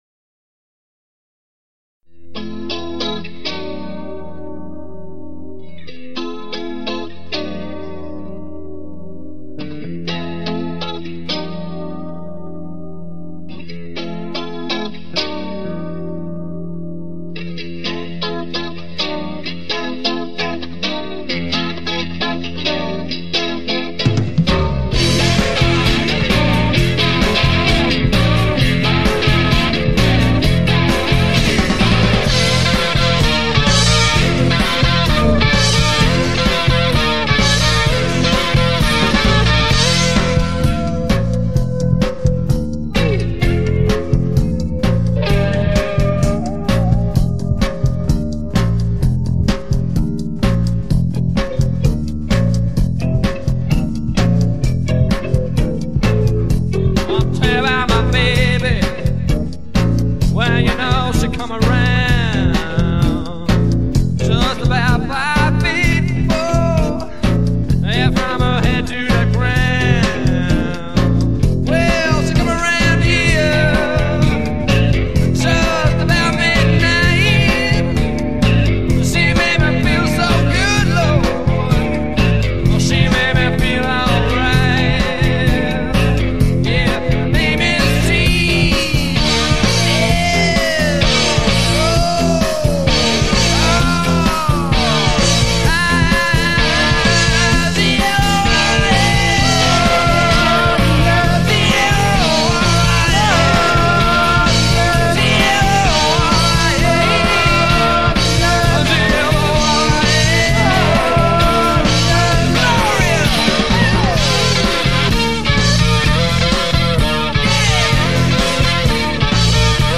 guitar/vocals